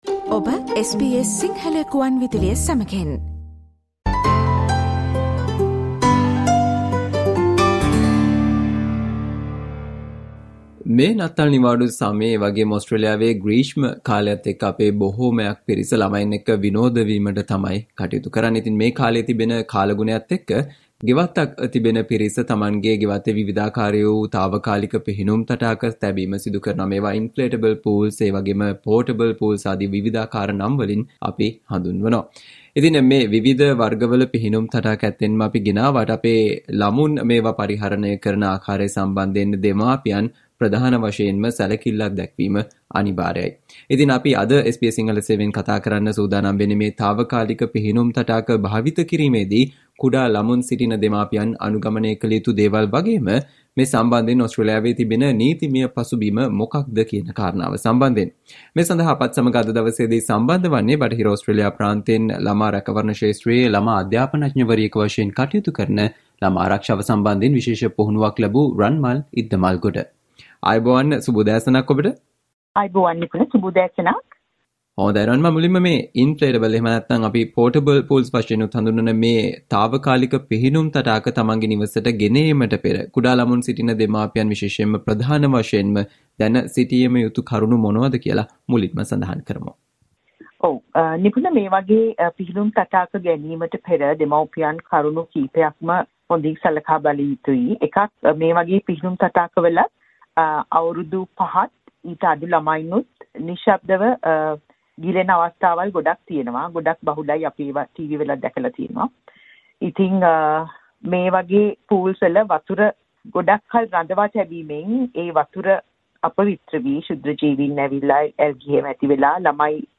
SBS Sinhala discussion on things you should know, before bringing home inflatable pools